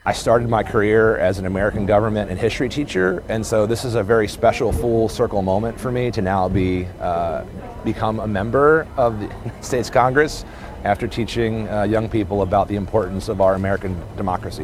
Olszewski told reporters his new role builds perfectly with his experience in education and government…